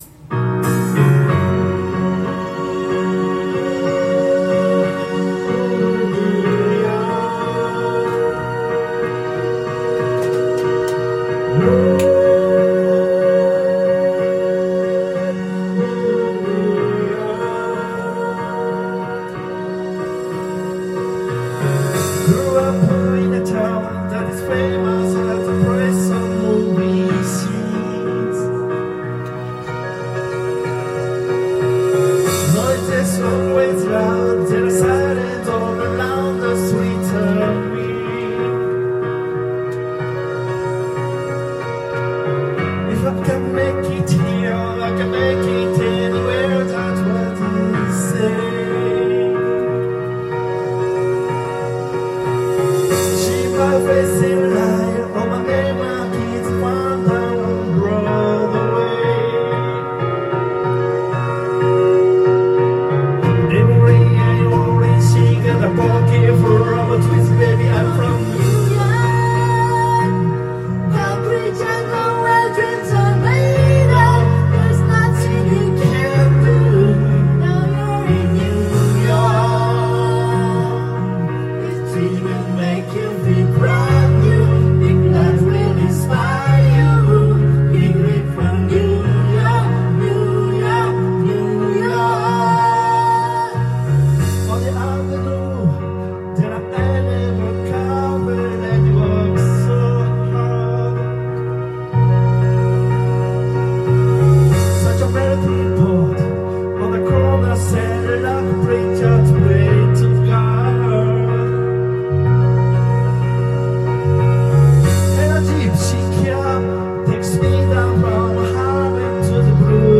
Duet & Chorus Night Vol. 18 TURN TABLE